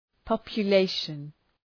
Προφορά
{,pɒpjə’leıʃən}